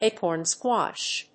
アクセントácorn squàsh